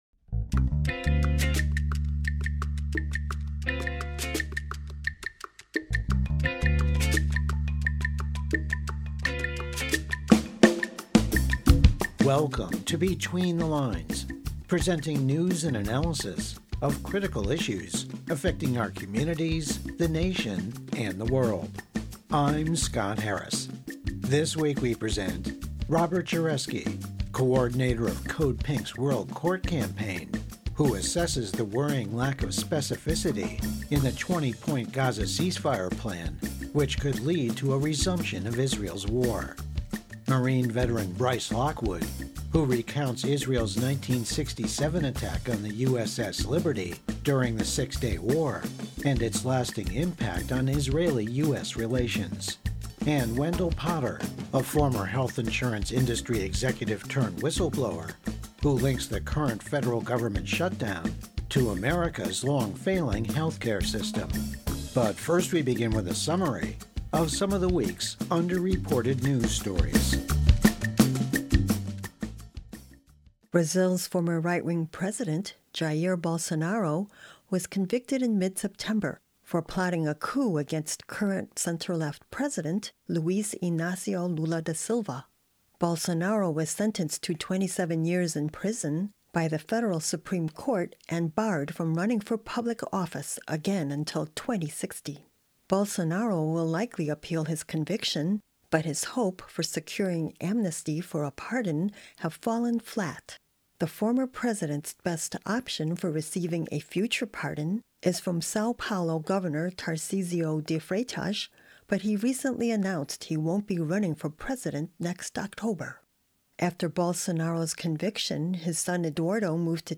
Program Type: Weekly Program